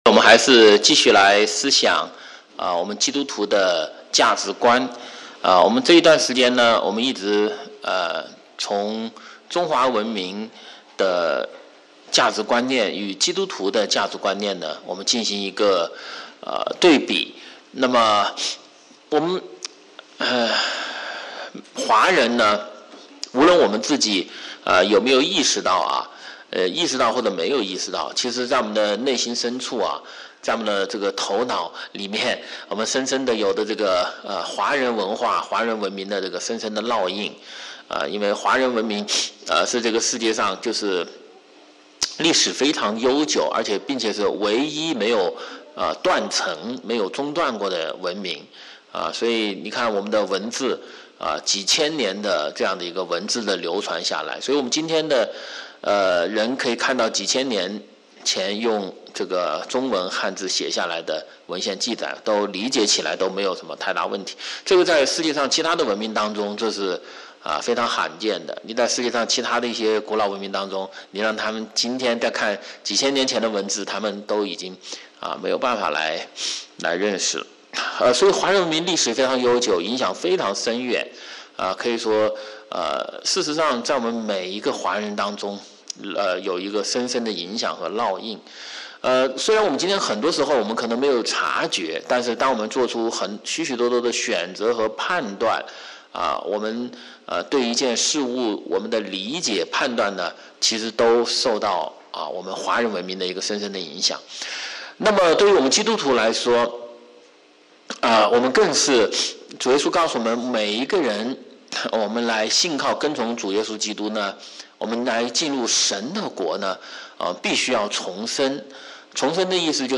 主日學